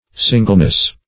Singleness \Sin"gle*ness\, n.